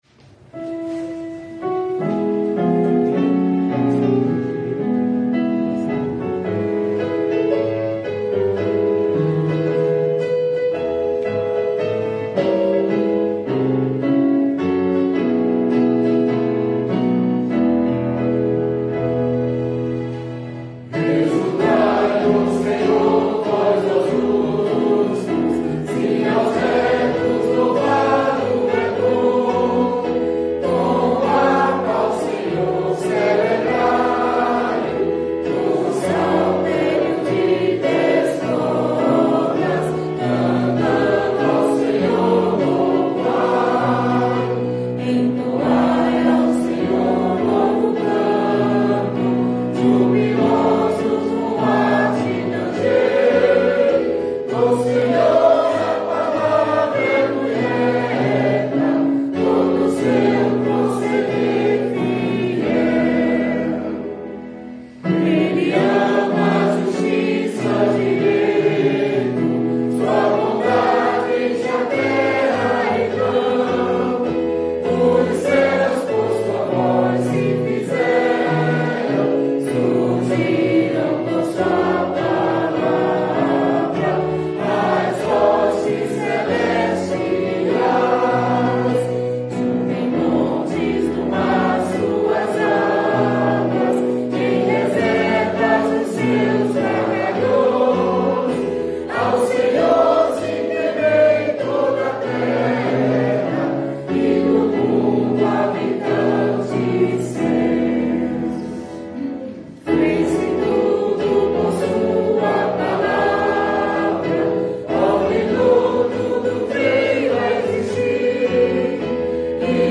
salmo_33B_cantado.mp3